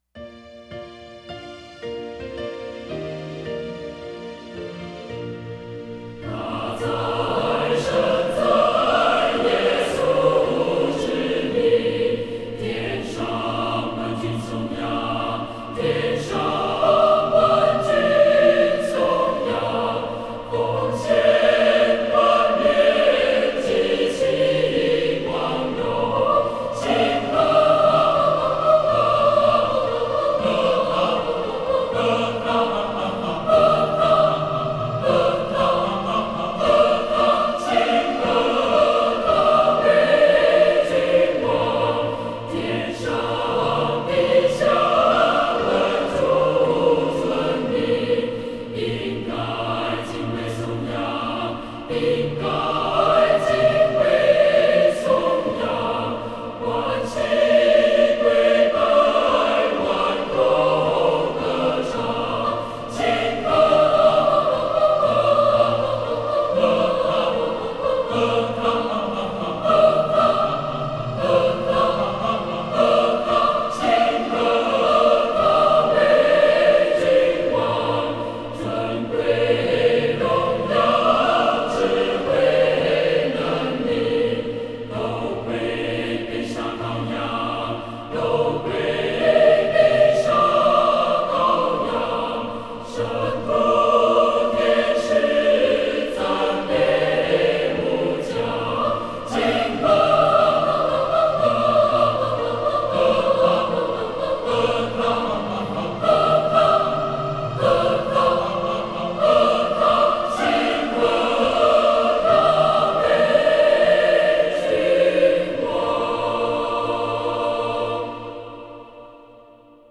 曲调华丽堂皇，引人入胜，如能用四部合唱则更为震撼。